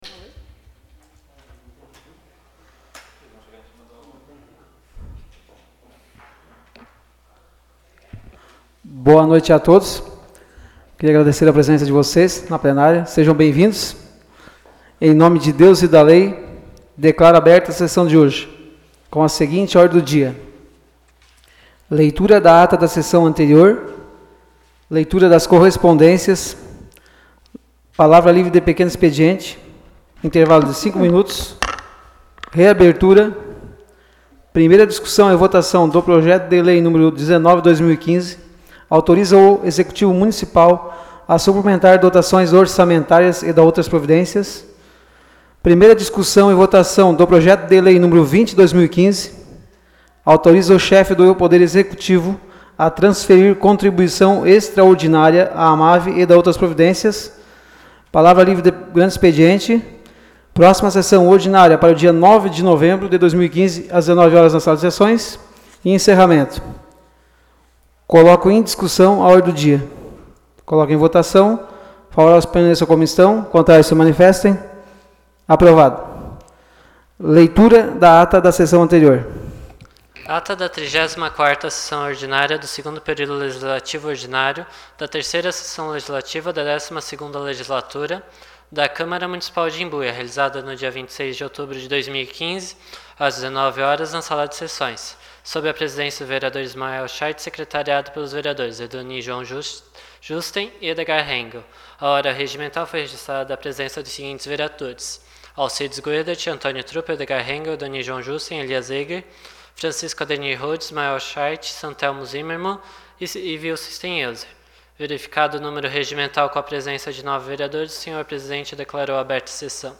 Áudio da Sessão Ordinária realizada em 03 de novembro de 2015.